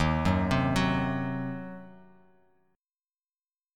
D#m6 Chord